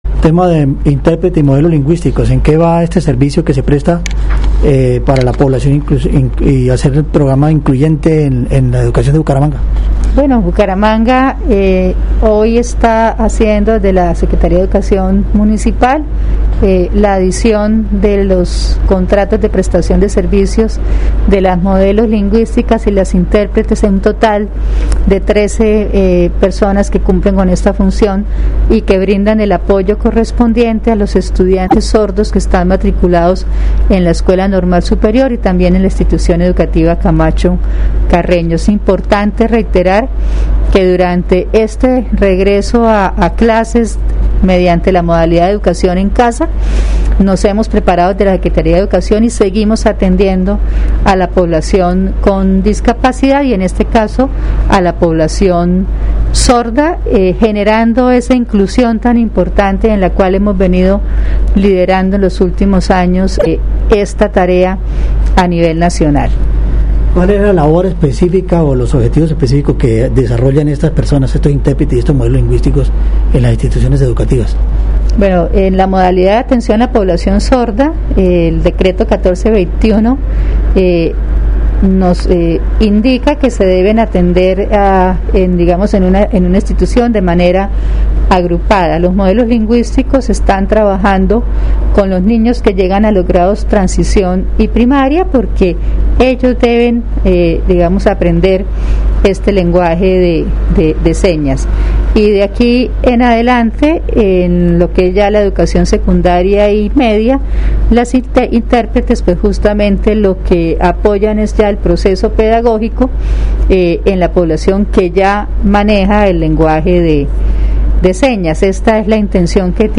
Ana Leonor Rueda secretaria Educación Bucaramanga